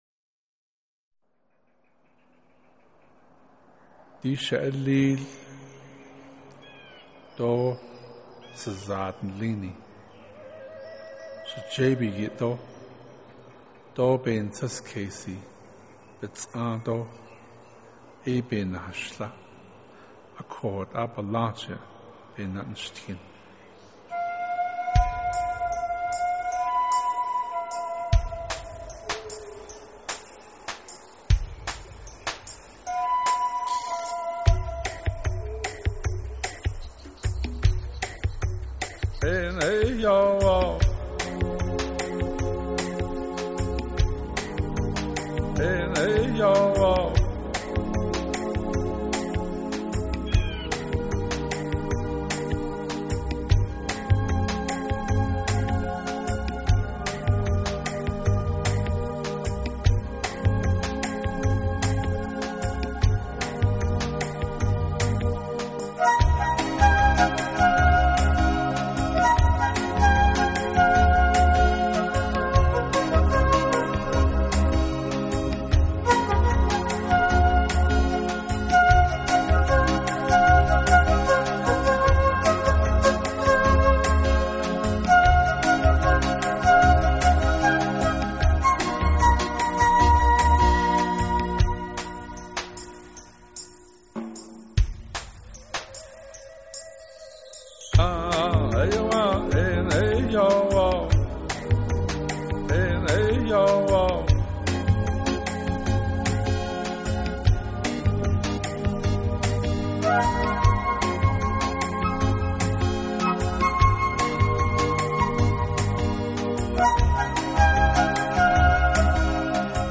他的音乐跨度较大，从Ambient(环境)到Healing(有治疗功用)的众多音乐风格。